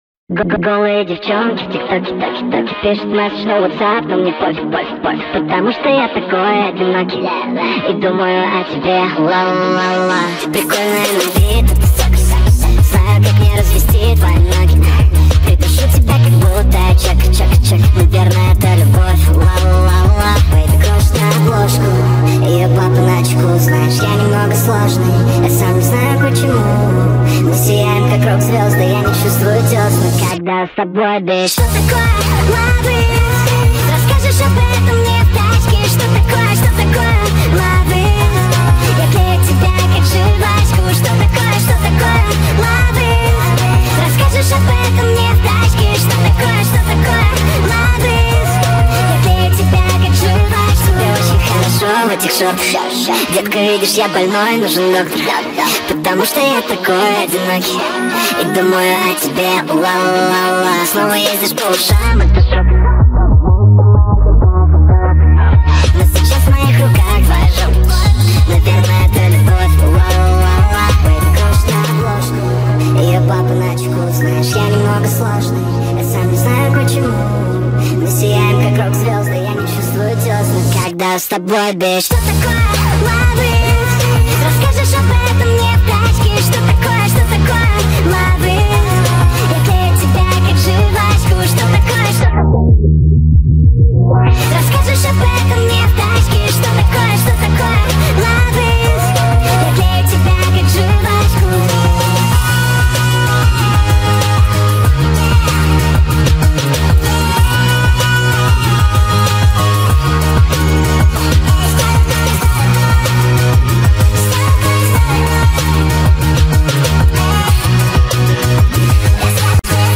это трек в жанре R&B